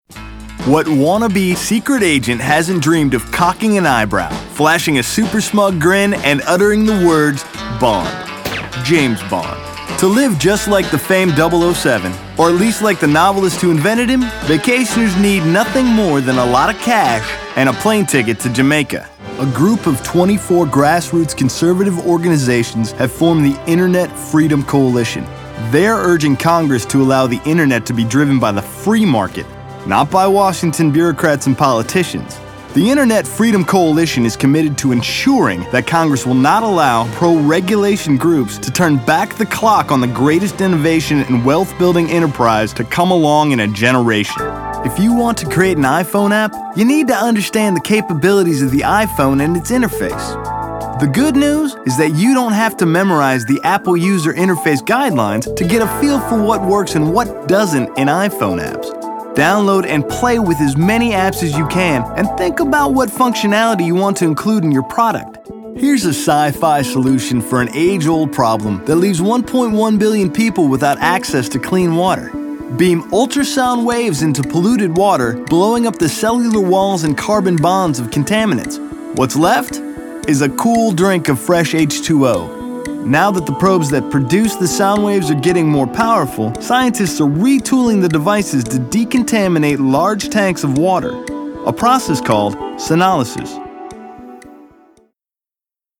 Laid back, guy next door, energetic, believable
middle west
Sprechprobe: Industrie (Muttersprache):